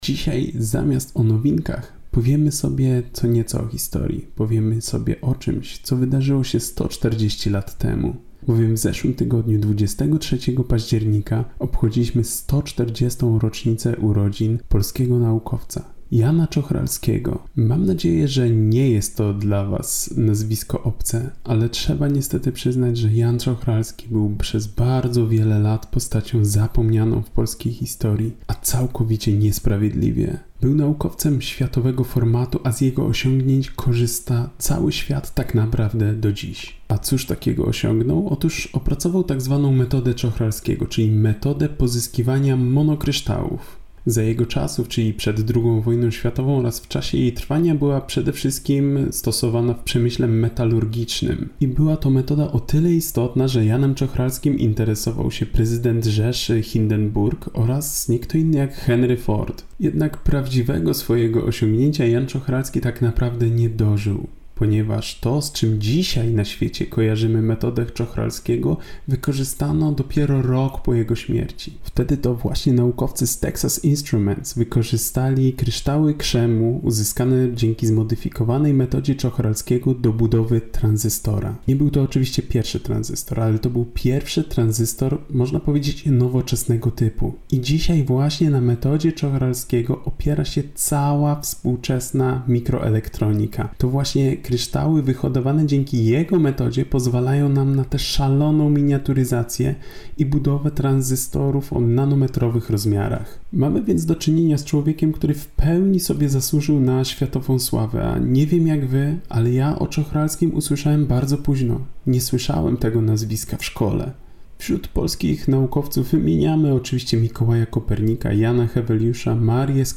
Postój z Nauką robimy sobie na antenie Radia UWM FM od poniedziałku do czwartku około 14:15 w audycji Podwójne Espresso.